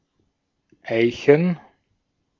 Ääntäminen
Etsitylle sanalle löytyi useampi kirjoitusasu: oak OAK Synonyymit oak tree Ääntäminen RP : IPA : /əʊk/ US : IPA : [əʊk] GenAm: IPA : /oʊk/ Haettu sana löytyi näillä lähdekielillä: englanti Käännös Ääninäyte Adjektiivit 1.